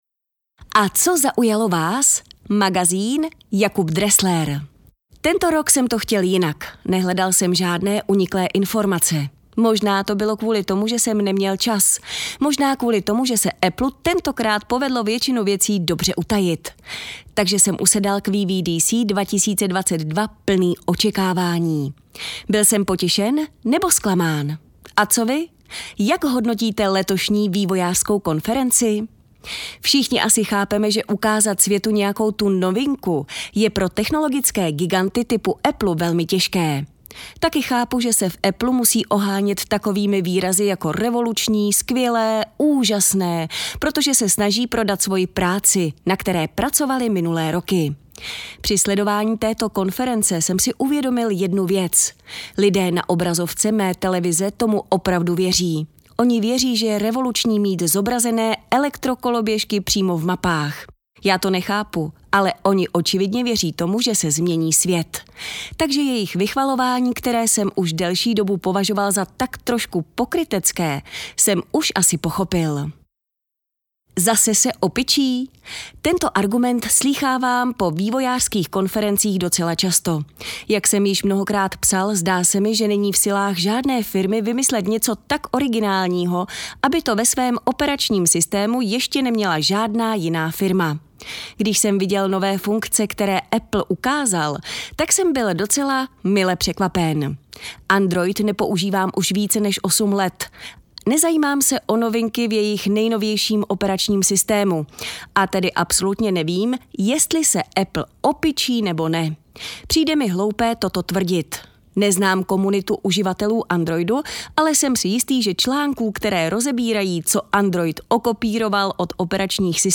Žánr: Dance.